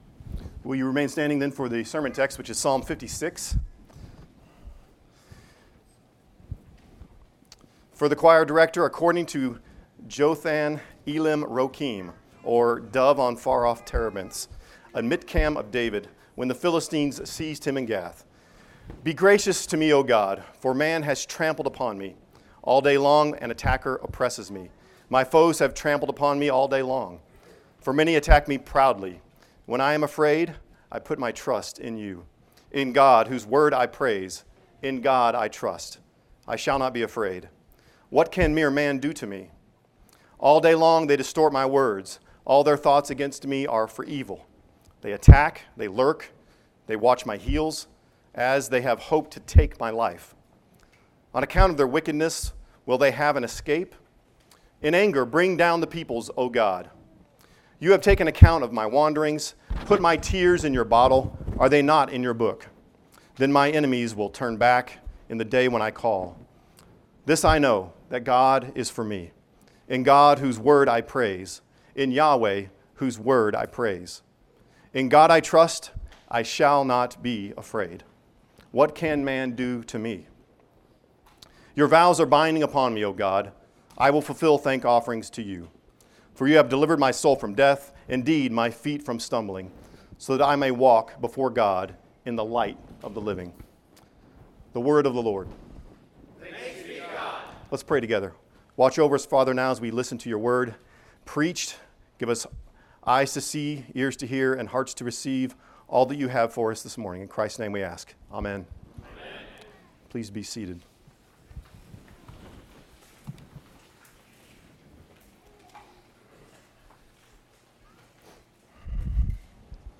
Psalm 56:1-13 Service Type: Sunday Sermon Download Files Bulletin Topics: Anxiety , Fear